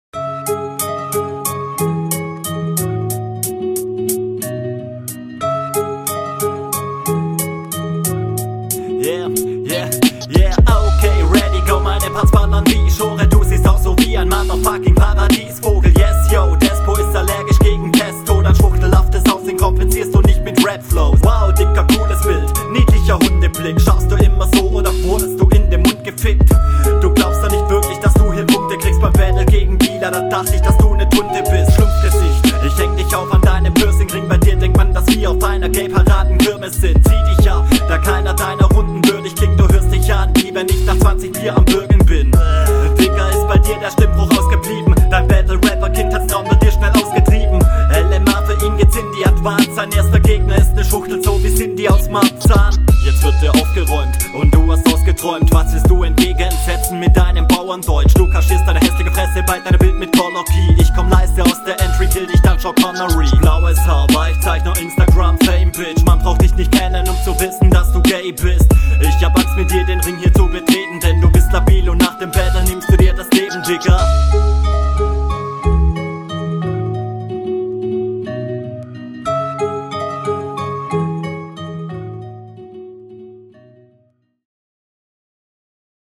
stimme bisi zu leise leider aber abgesehen davon ganz okayer sound. bist gut on point.
Beat kommt schon fresh!